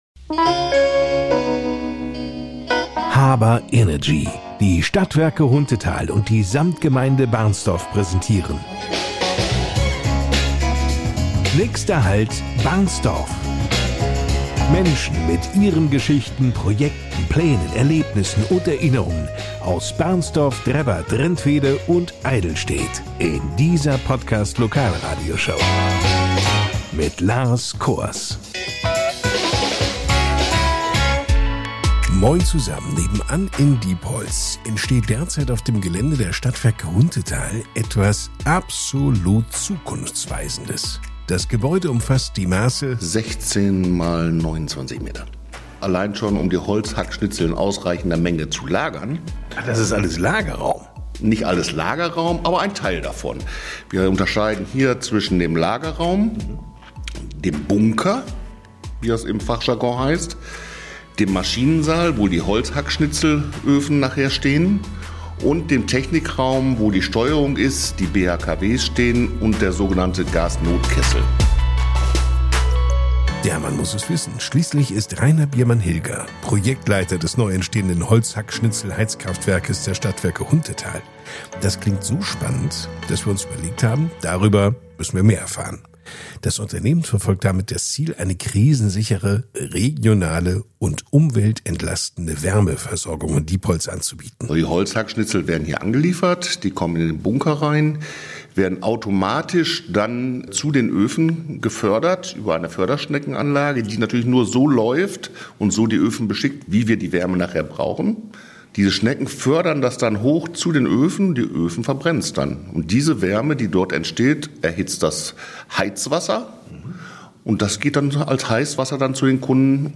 Die Podcast-Lokalradioshow